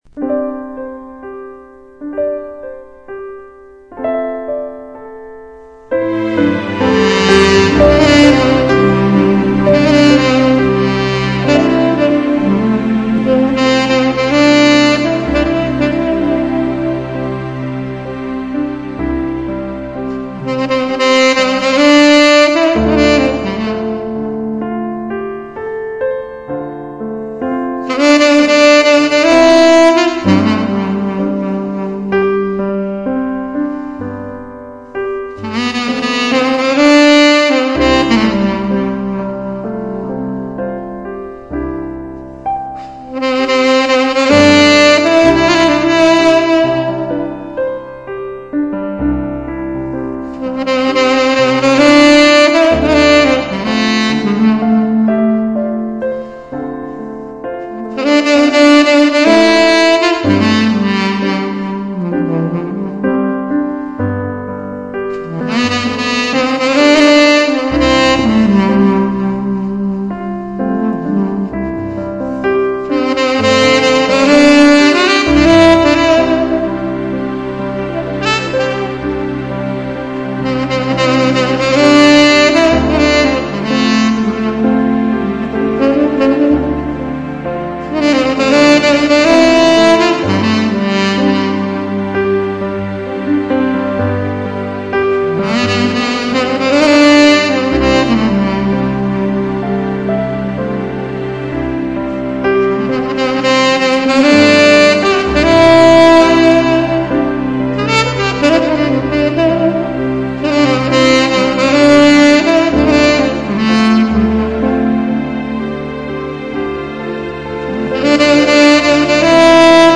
Instr